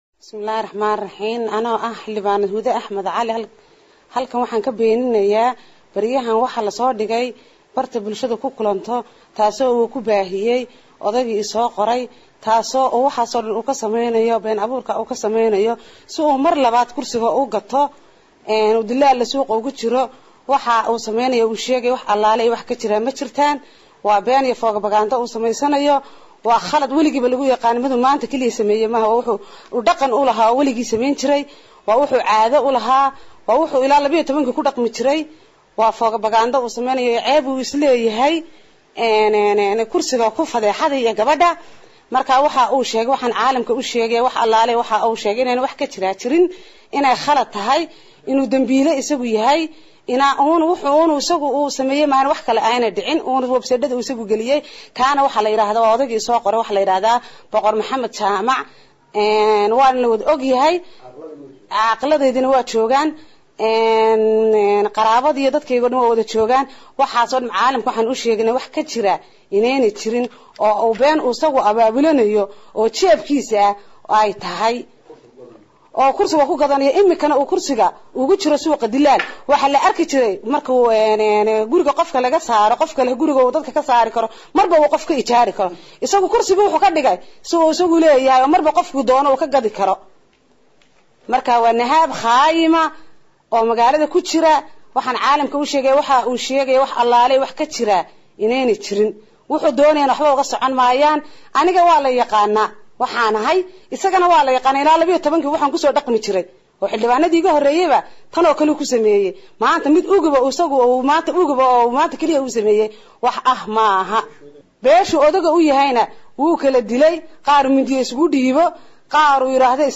Hoos ka dhegeyso beeninta xildhibaanada
XILDHIBAANAD-Hudda-oo-ka-hadashay-In-Sedex-Nin-ay-Hal-Mar-guursatay-CIDA-KA-DAMBEYSA-ARINTAAS.mp3